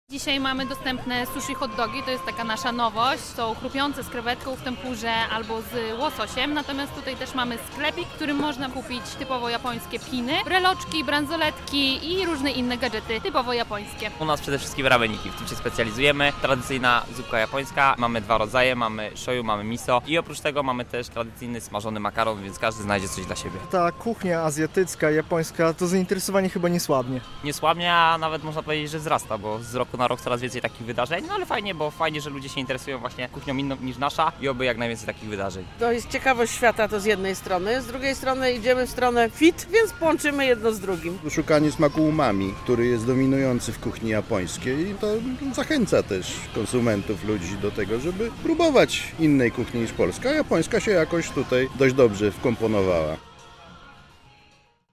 W ogrodzie dolnym Pałacu Branickich czeka mnóstwo stoisk z azjatyckim jedzeniem i nie tylko.